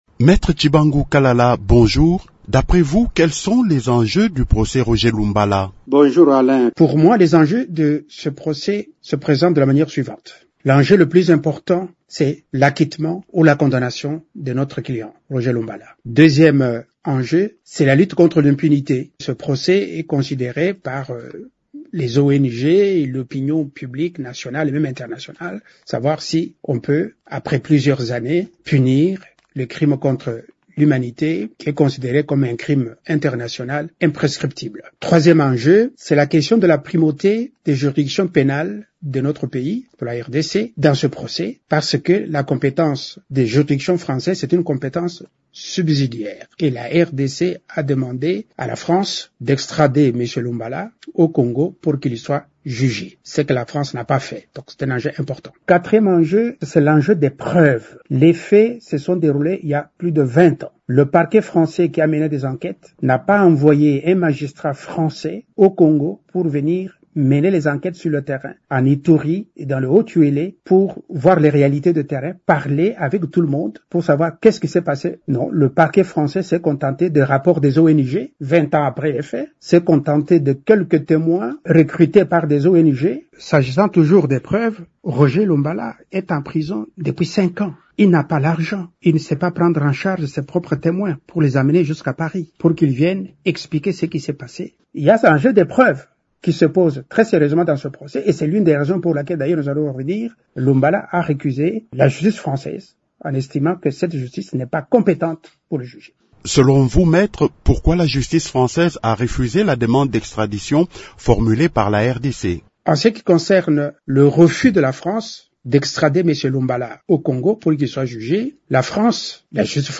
Dans une interview accordée à Radio Okapi le jeudi 13 novembre